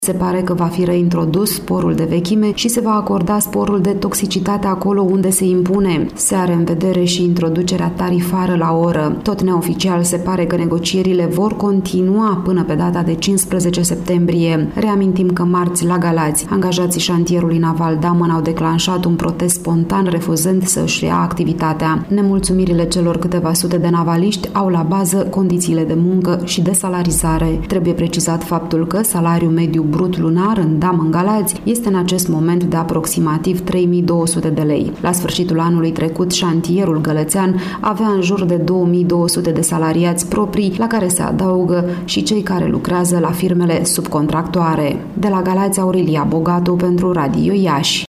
Ce alte prevederi conţin propunerile convenite în urma negocierilor, ştie corespondenta noastră